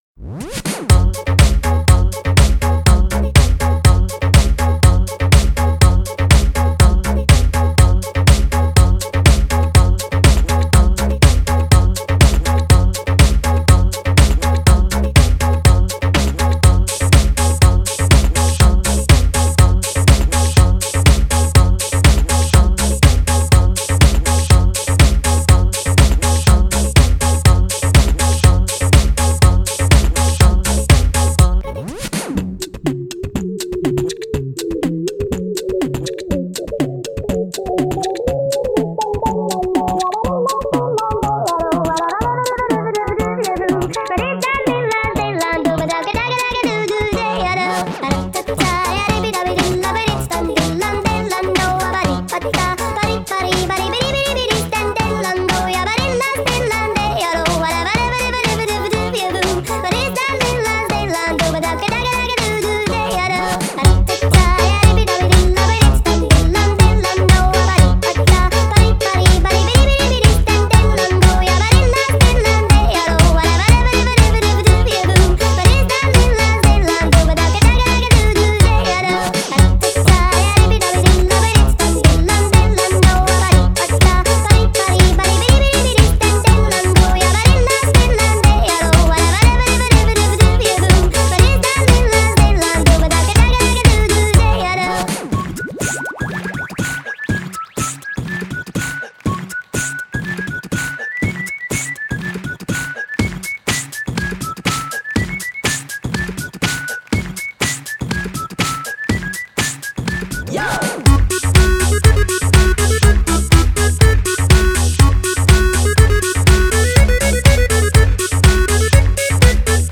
[卡通舞曲]
网络上窜红的舞曲 可爱且性感的声音